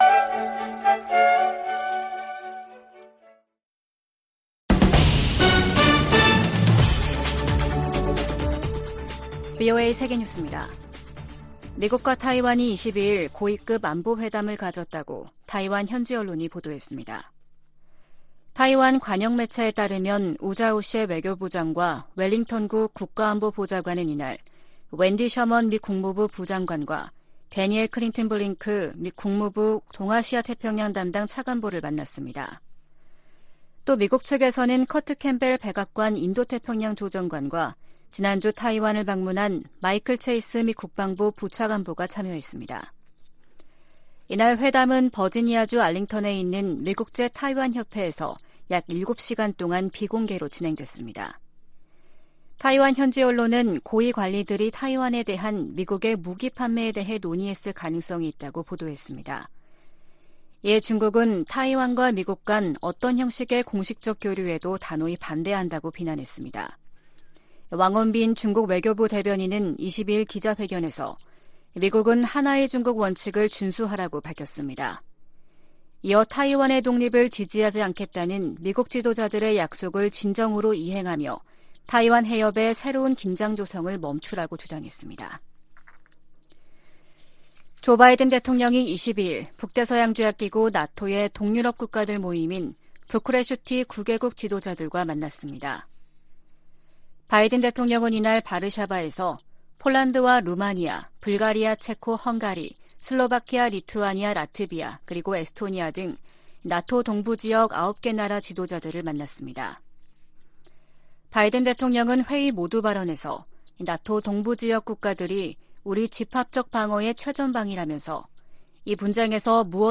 VOA 한국어 아침 뉴스 프로그램 '워싱턴 뉴스 광장' 2023년 2월 23일 방송입니다. 미 하원 군사위원장이 북한의 미사일 위협에 맞서 본토 미사일 방어망을 서둘러 확충할 것을 바이든 행정부에 촉구했습니다. 한국 군 당국은 북한이 대륙간탄도미사일(ICBM)의 모든 발사 능력을 보유하고 있고 정상각도 시험발사 시점을 재고 있는 단계라고 밝혔습니다. 영국과 프랑스, 독일을 비롯한 주요 국가들이 북한의 ICBM 발사를 일제히 규탄했습니다.